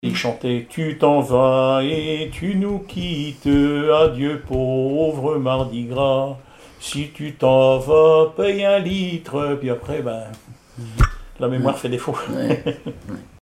Chants brefs
circonstance : carnaval, mardi-gras
Témoignages sur le cycle calendaire et des extraits de chansons maritimes
Pièce musicale inédite